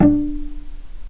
vc_pz-D4-pp.AIF